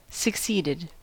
Ääntäminen
Ääntäminen US Haettu sana löytyi näillä lähdekielillä: englanti Käännöksiä ei löytynyt valitulle kohdekielelle. Succeeded on sanan succeed partisiipin perfekti.